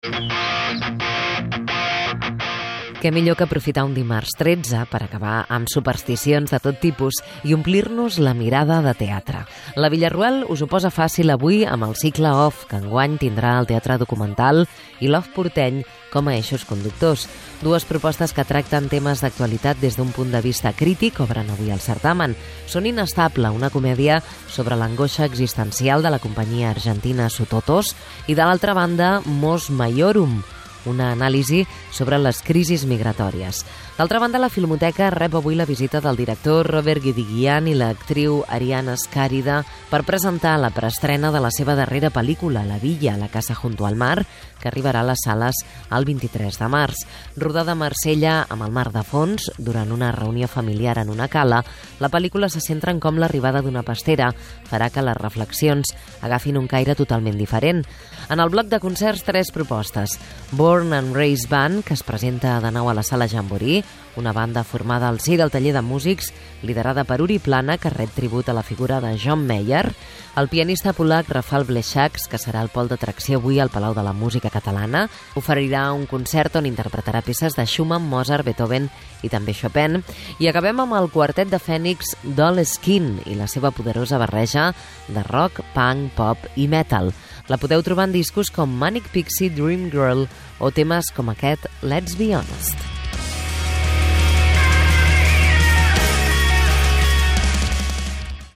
Agenda cultural
FM